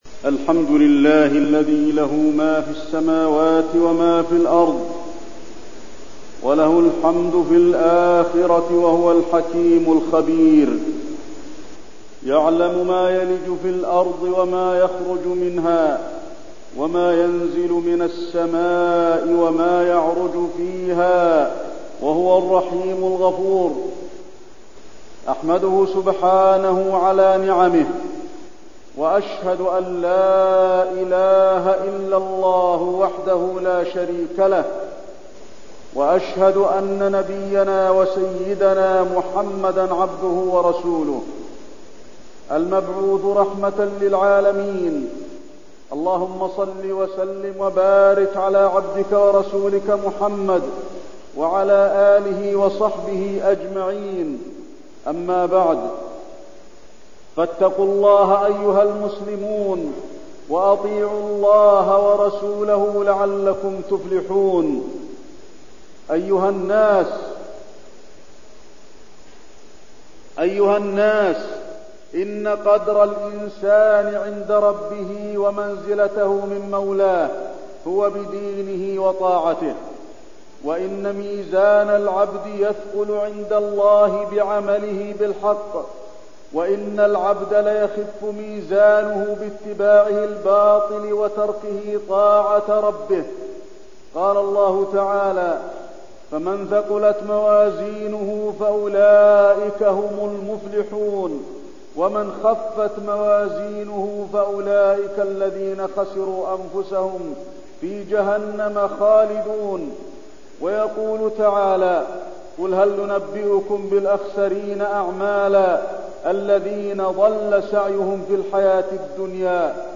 تاريخ النشر ٧ جمادى الأولى ١٤٠٩ هـ المكان: المسجد النبوي الشيخ: فضيلة الشيخ د. علي بن عبدالرحمن الحذيفي فضيلة الشيخ د. علي بن عبدالرحمن الحذيفي الصلاة